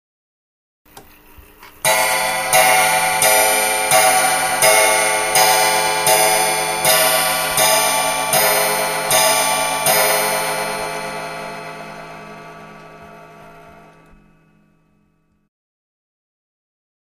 Clock 5, bozak, 1823, Strikes 12; Czech Bozak Mantelpiece Clock, Dated 1823, Strikes 12 O'clock, With Brass 'coil' Bell